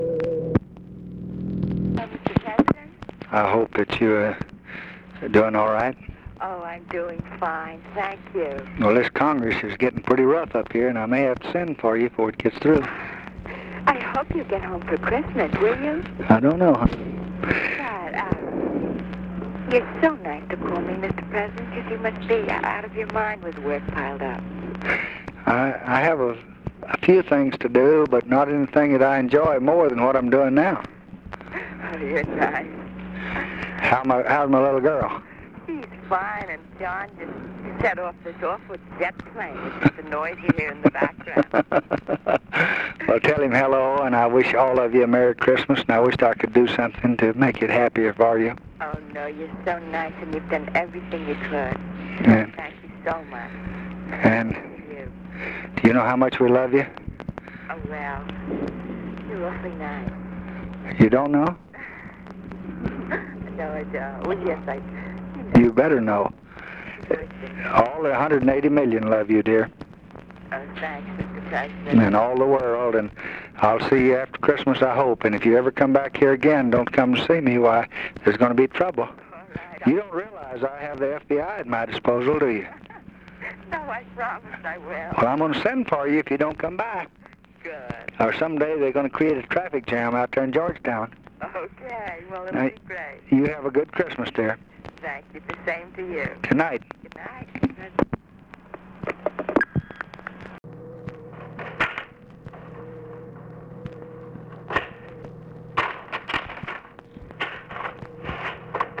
Conversation with JACQUELINE KENNEDY, December 24, 1963
Secret White House Tapes